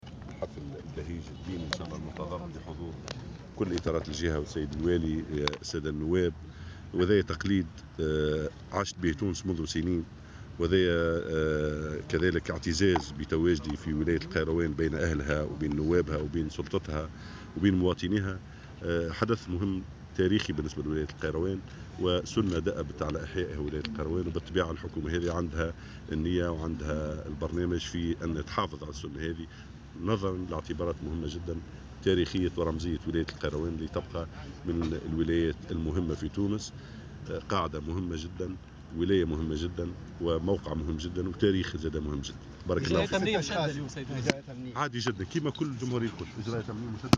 شارك مساء أمس الاثنين وزير الداخلية ناجم الغرسلي في احتفالات ليلة القدر بجامع عقبة ابن نافع بالقيروان.